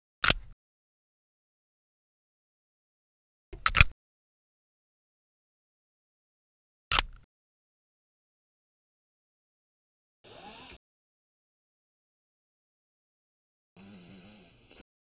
Car Seatbelt Latch Sound
transport
Car Seatbelt Latch